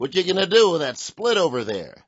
gutterball-3/Gutterball 3/Commentators/Baxter/wack_whatdowiththatsplit.wav at main